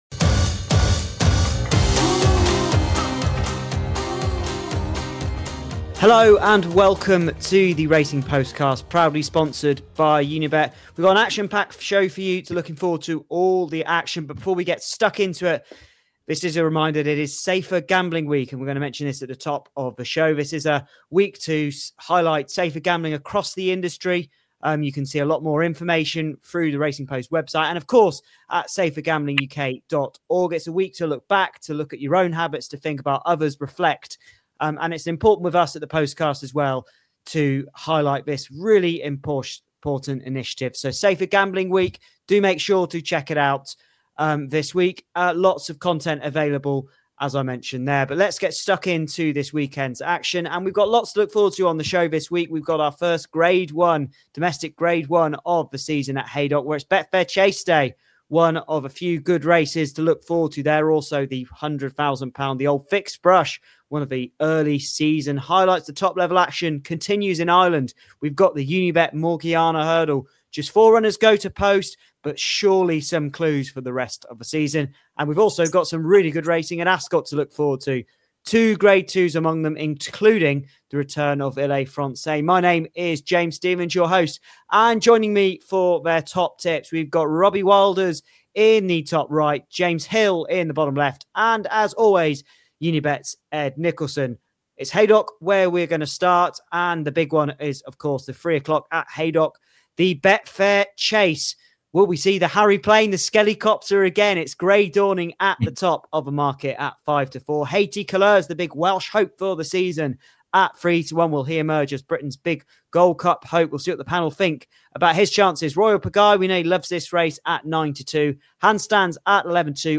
We’ll be taking a deep dive into the feature event, the Betfair Chase at Haydock, where all eyes are on the favourite Grey Dawning. The panel discuss whether he’s the one to beat or if there’s a smarter angle lurking in behind. Expect expert tips, sharp betting insights, and in-depth analysis across all the major races on the ITV schedule.